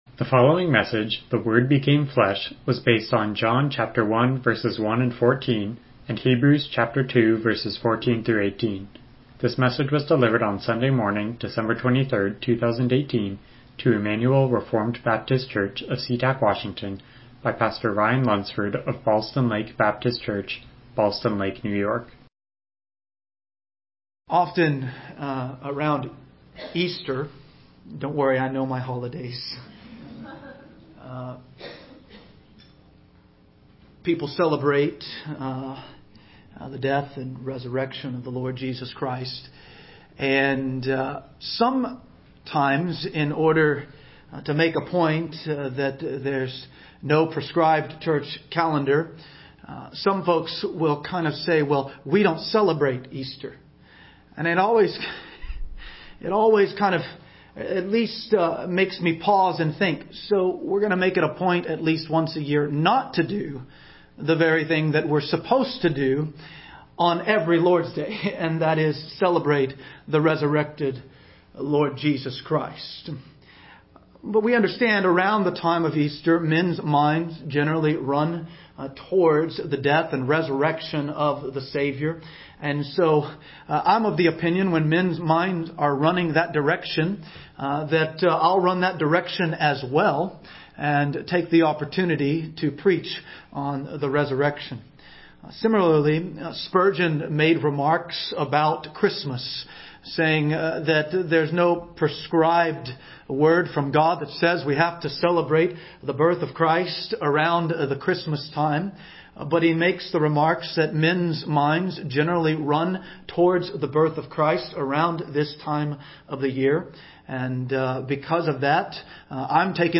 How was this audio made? Hebrews 2:14-18 Service Type: Morning Worship « Qualifications for Elders